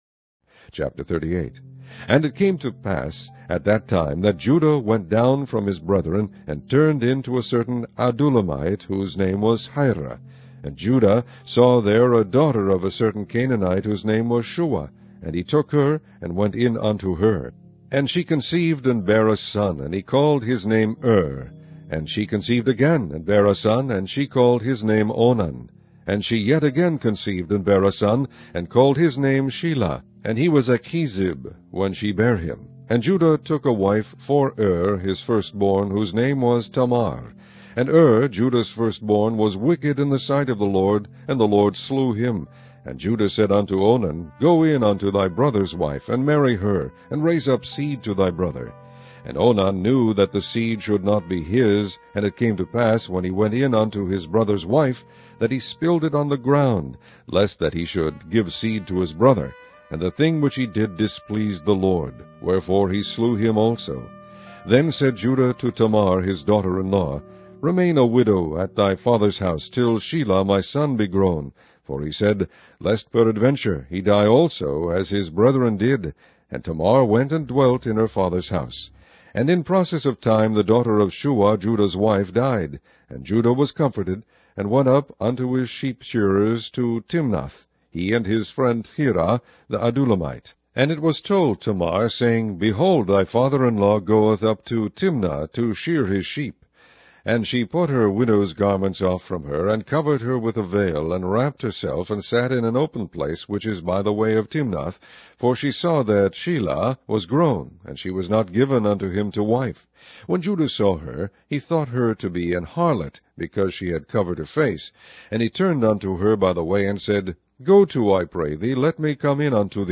Online Audio Bible - King James Version - Genesis